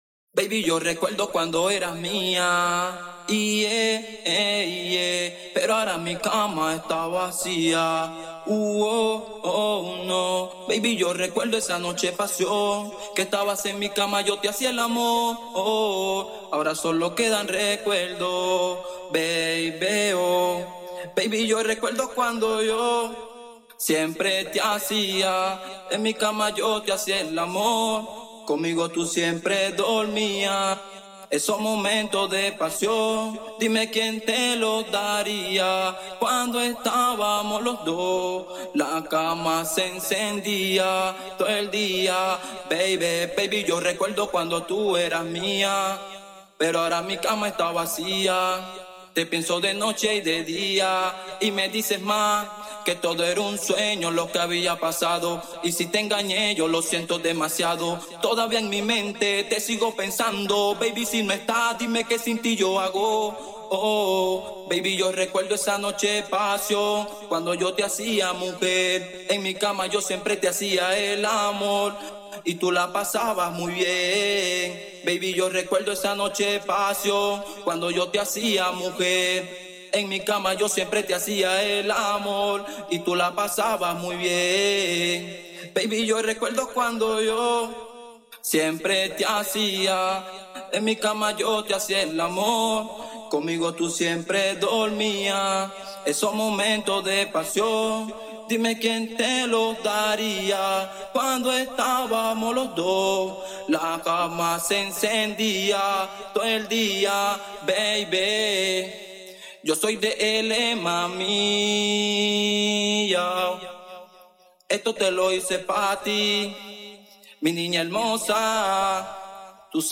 VOZ AFINADA para mastering.mp3